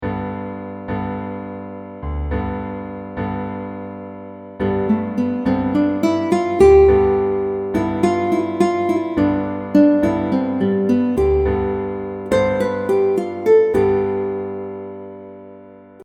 Einige Musikmodi werden häufiger verwendet als andere, und jeder hat seinen eigenen Klang.
Mixolydisch: Ein Dur-Modus mit einer erniedrigten 7. Stufe. Der Blues-Modus!
G-mixolydian.mp3